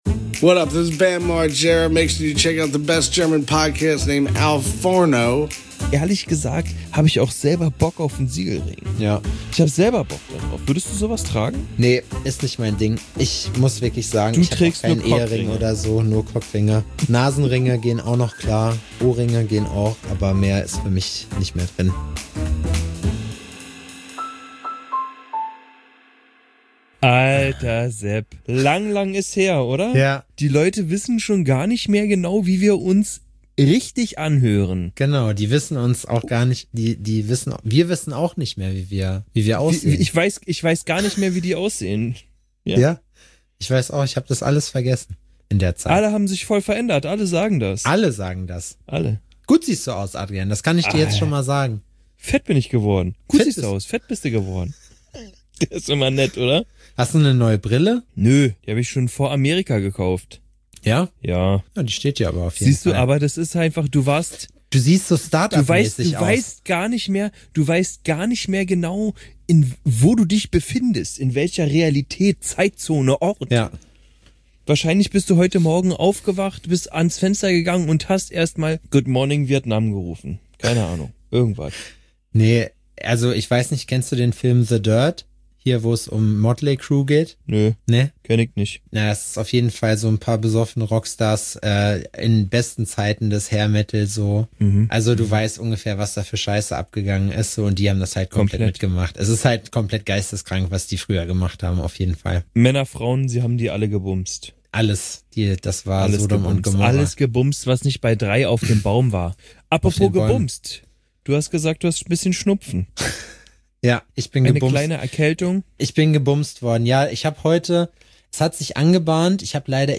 Die Jungs sind wieder im heimischen Podcaststudio zu Gange und resümieren über Frankreich, die Sprachbarriere und was das eigentlich für 1 Messe-Life ist.